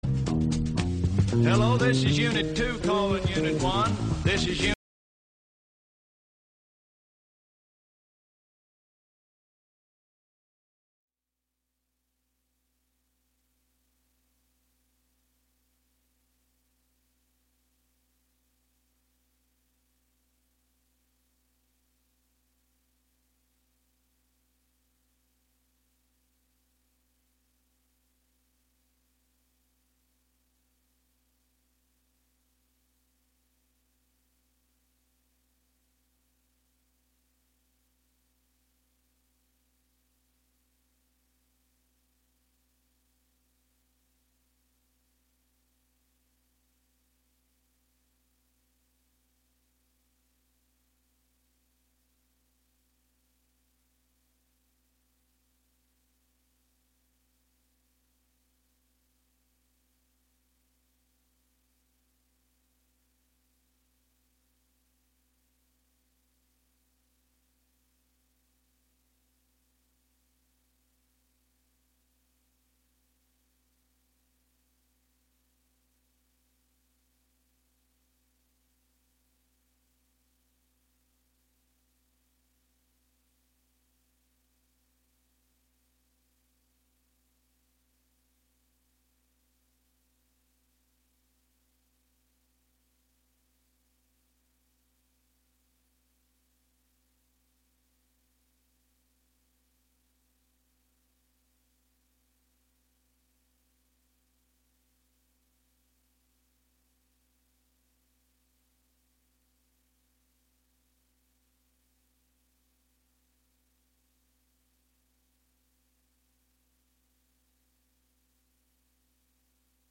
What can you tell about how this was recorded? Broadcast live HiLo in Catskill.